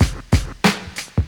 • 103 Bpm 2000s Hip-Hop Drum Loop F# Key.wav
Free breakbeat - kick tuned to the F# note. Loudest frequency: 1476Hz